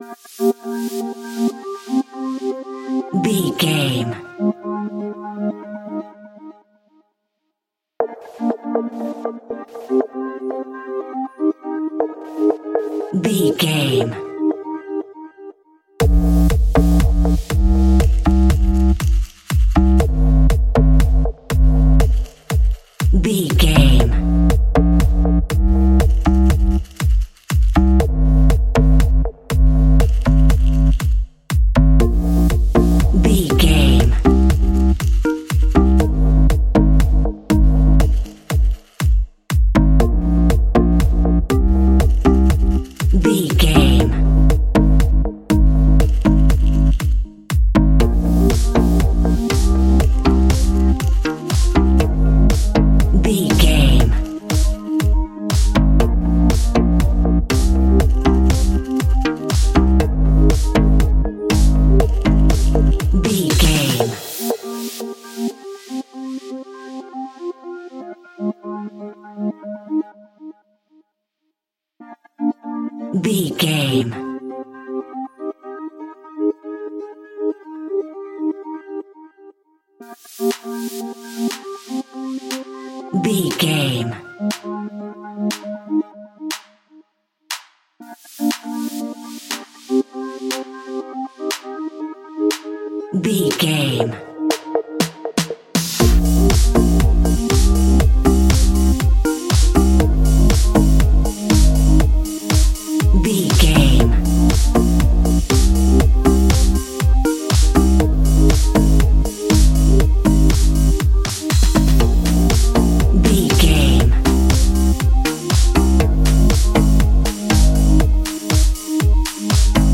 Uplifting
Aeolian/Minor
bouncy
energetic
synthesiser
drum machine
sleigh bells